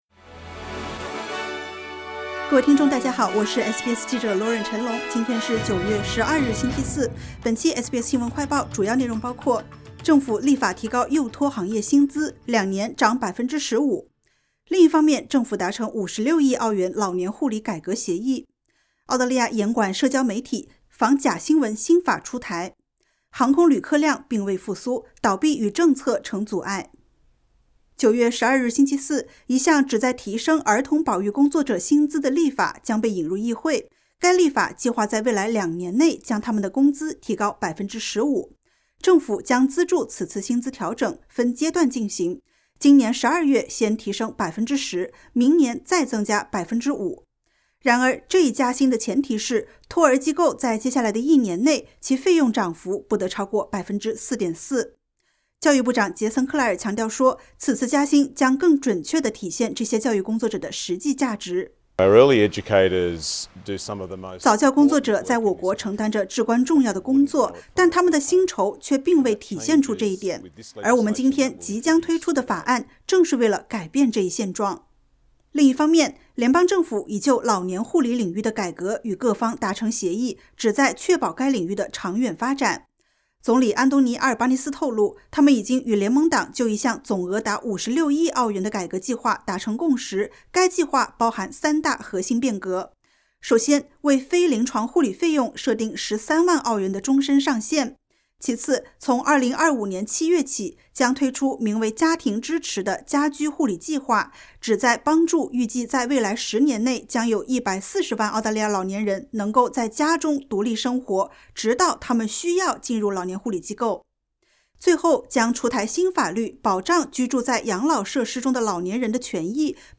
【SBS新闻快报】政府立法提高幼托行业薪资 两年涨15%